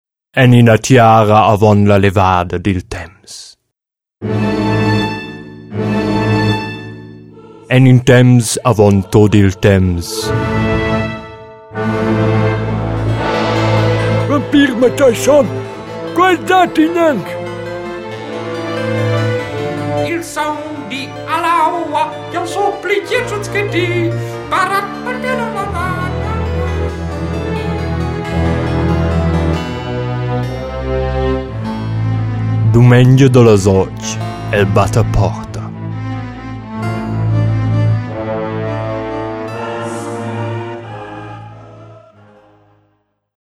Trailer
LE DiSQUE - Vampirs Trailer.MP3